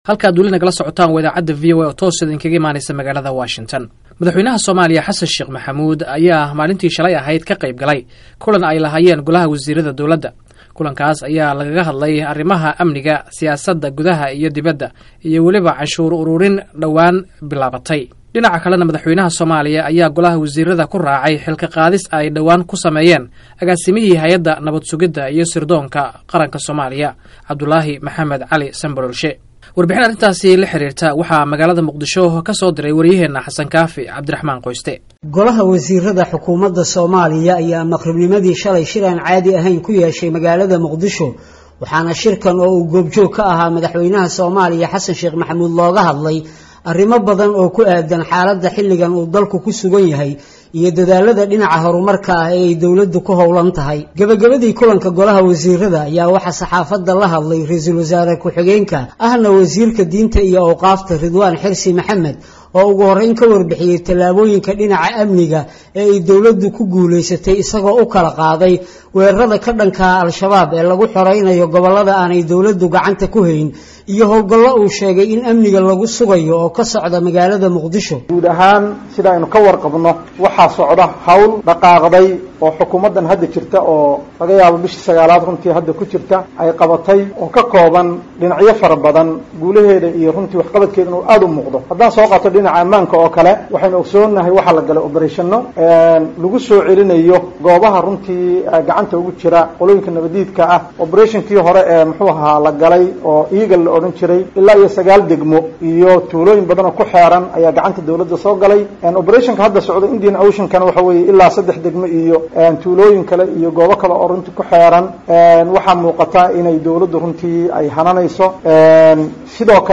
Wareysiga Sambaloolshe